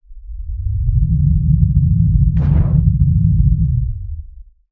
harvesterretract.wav